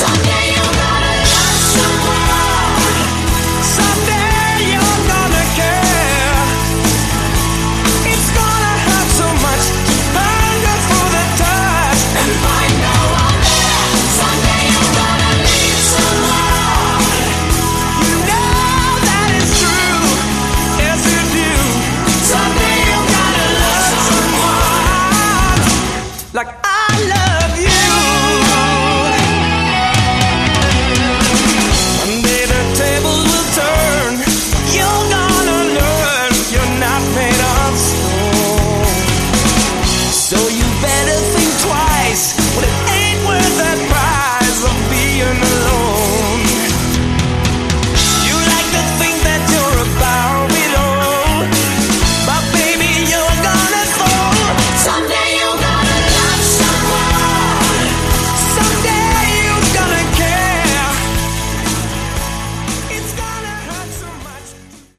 Category: Melodic Hard Rock
Vocals
Guitars
Bass
Drums
Keyboards